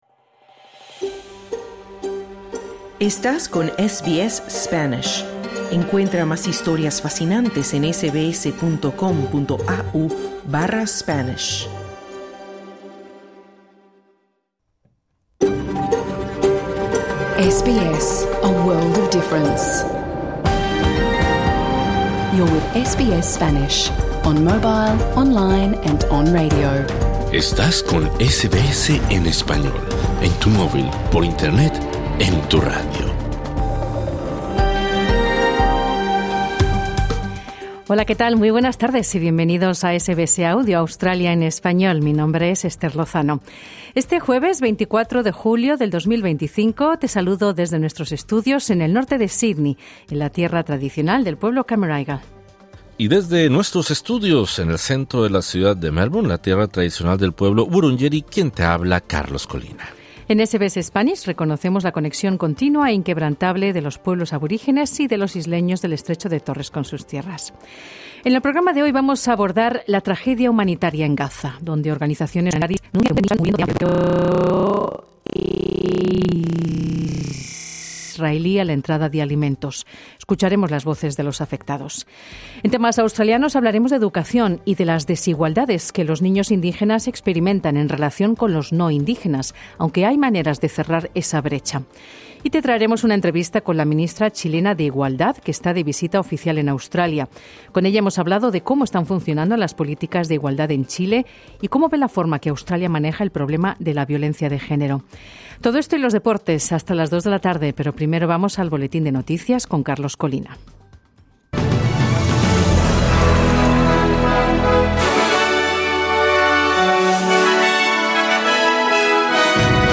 Conversamos con la Ministra de la Mujer y la Equidad de Género de Chile, Antonia Orellana, sobre el motivo de su visita a Australia e interés en la ley impulsada por Australia para combatir la violencia de género en línea. También abordamos la tragedia humanitaria en Gaza, y hablamos de las desigualdades de los niños indígenas australianos.
Escucharemos las voces de los afectados.